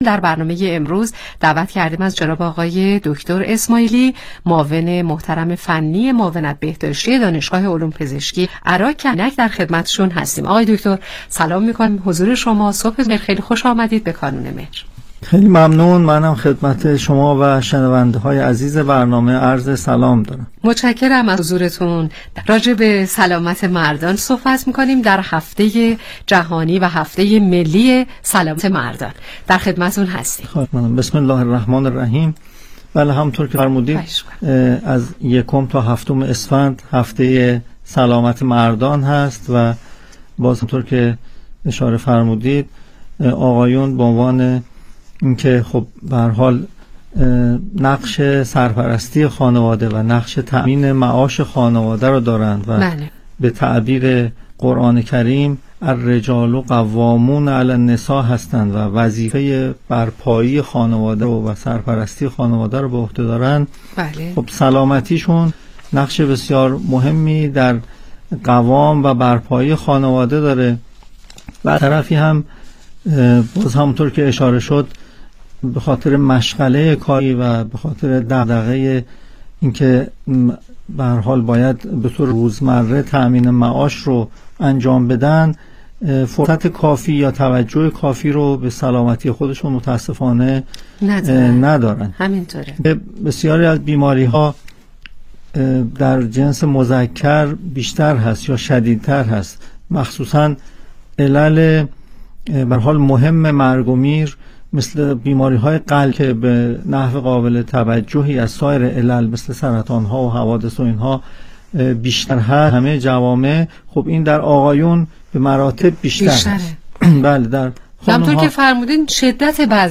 برنامه رادیویی کانون مهر با موضوع اهمیت سبک زندگی سالم در ناباروری مردان